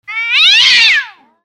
猫同士が争っているとき威嚇の声は「シャーッ」と発しますが、それ以上となると「ミャ〜オ〜ッ！！」と発し争うことがあります。
怒った猫の鳴き声「ミャ〜オッ！！」 着信音